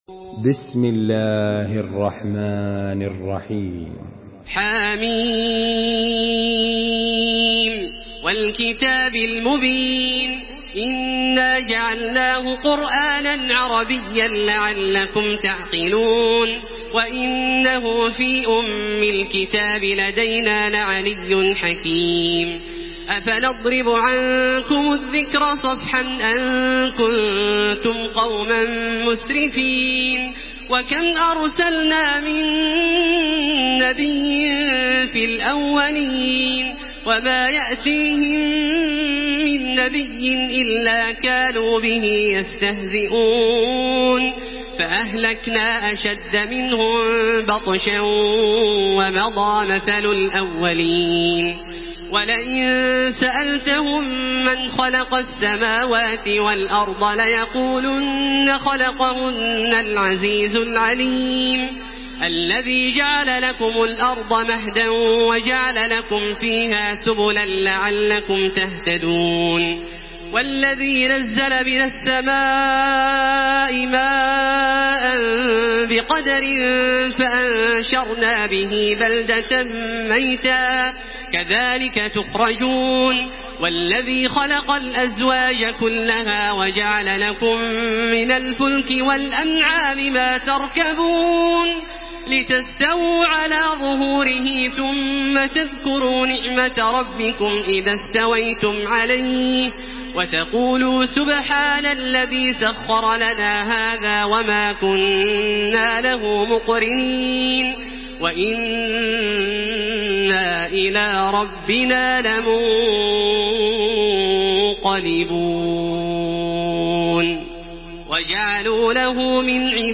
تحميل سورة الزخرف بصوت تراويح الحرم المكي 1433
مرتل